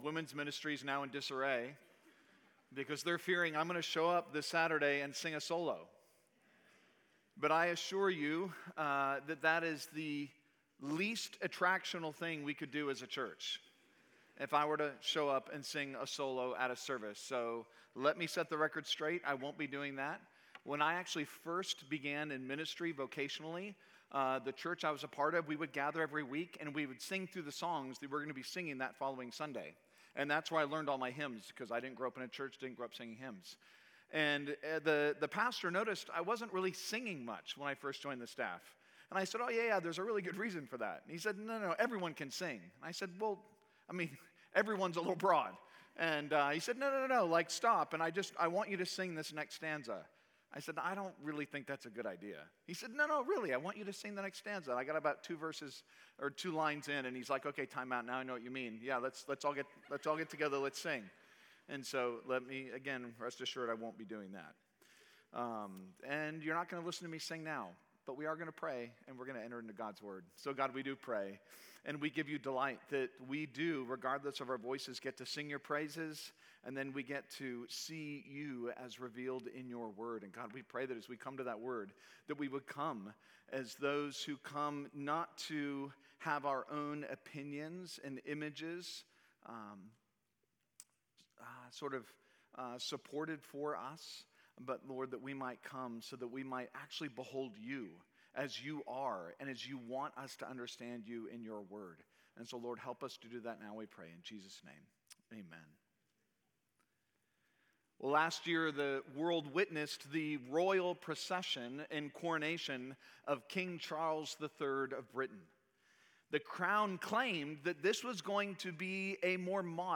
Sermon Audio | University Baptist Church